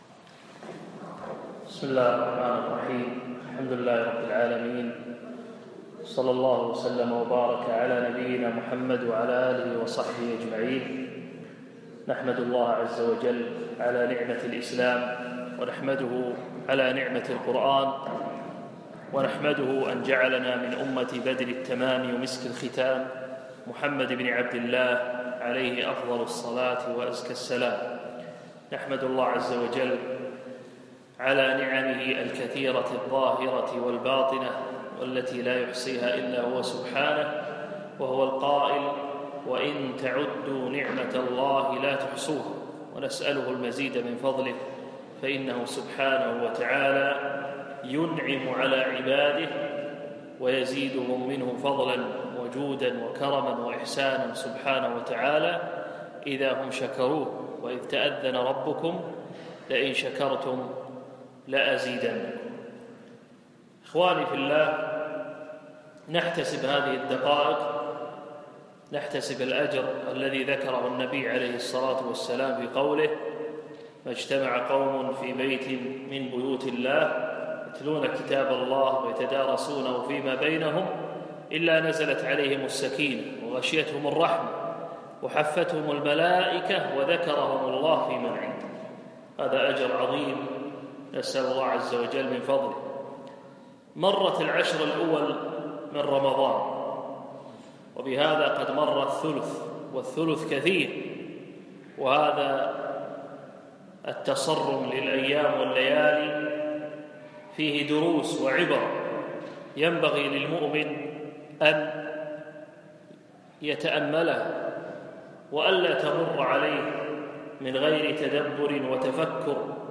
الأثنين 10 رمضان 1438 الموافق 5 6 2017 بمسجد سعد بن عبادة خيطان